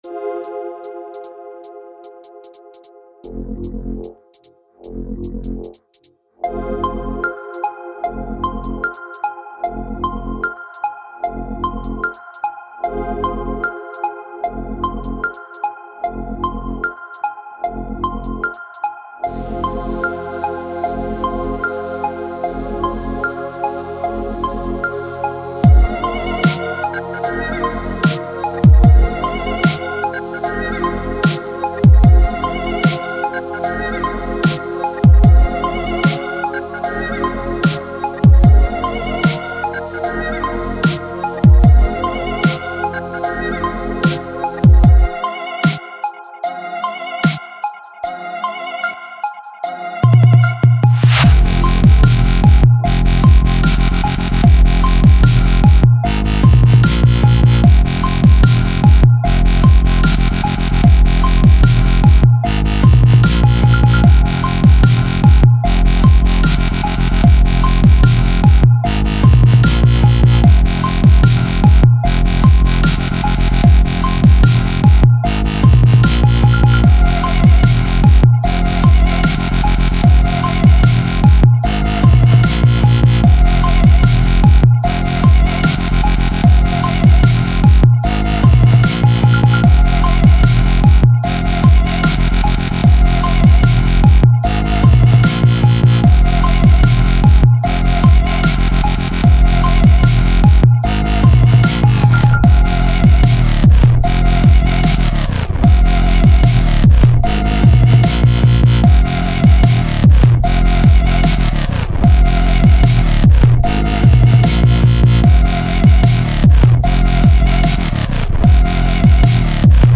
Скачать Минус